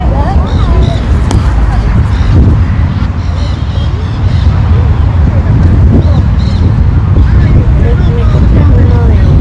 Nanday Parakeet (Aratinga nenday)
Location or protected area: Parque 3 de Febrero
Detailed location: Lago del Planetario
Condition: Wild
Certainty: Photographed, Recorded vocal